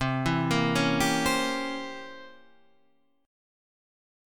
C 7th Flat 9th